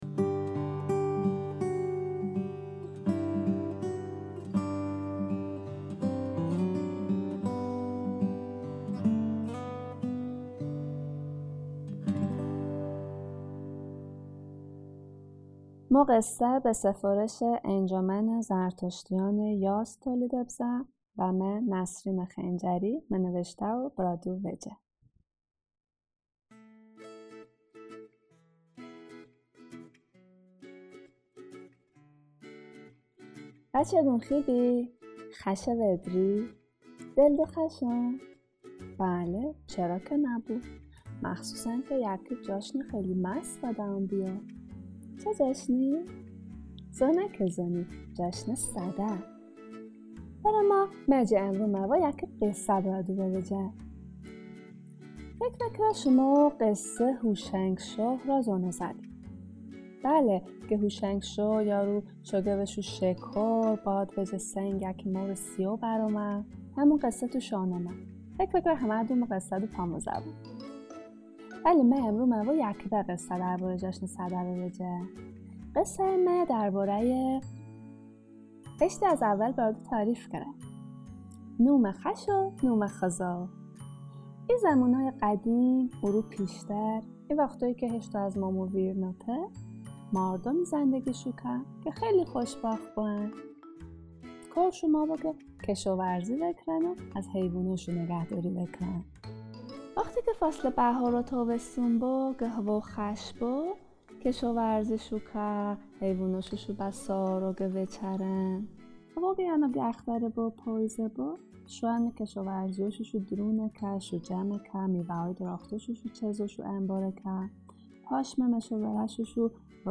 انجمن زرتشتیان یزد از تولید داستان‌های صوتی ویژه‌ی کودکان در راستای پاسداشت گویش زرتشتی برای نسل جدید خبر داد.